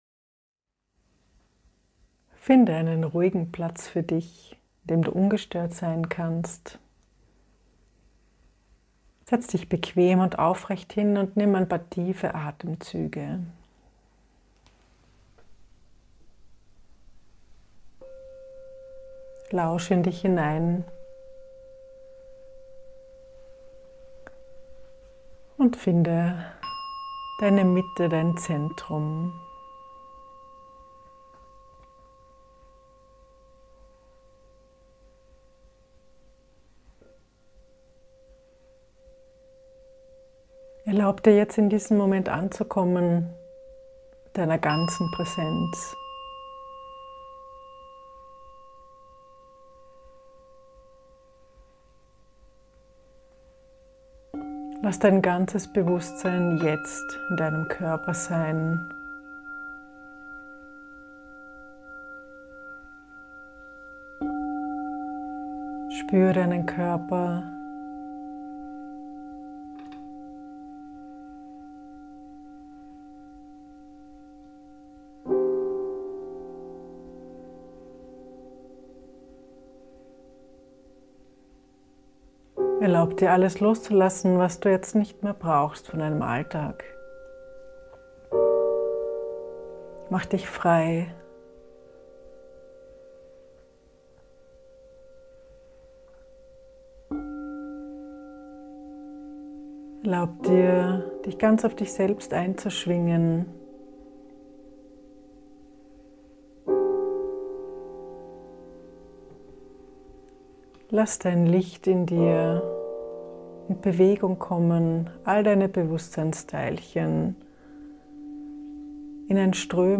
Finde deine starke Mitte und mache deine Lebendigkeit wieder wahrnehmbar durch tiefe Entspannung. Diese sanfte ambient music unterstützt dich dabei, in dein multidimensionales Sein einzutauchen und deine einzigartige Schöpferenergie zu erfahren.
piano
Kristallklangschalen